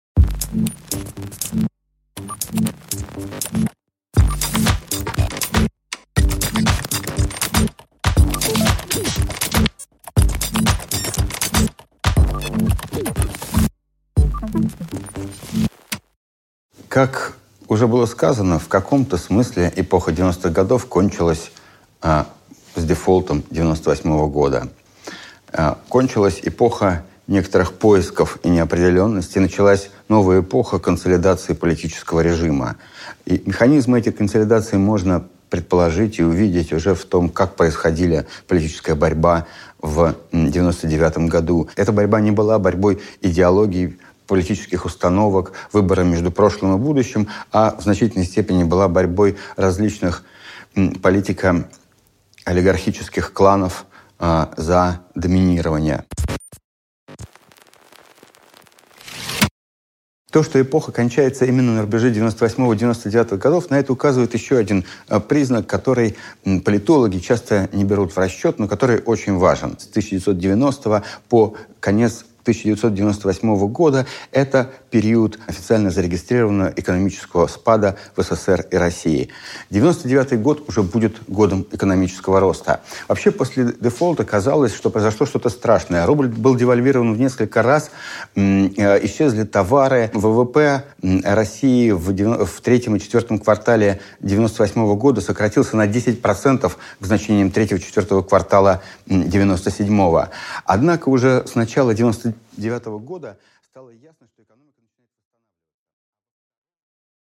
Аудиокнига Между олигархией и авторитаризмом | Библиотека аудиокниг